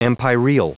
Prononciation du mot empyreal en anglais (fichier audio)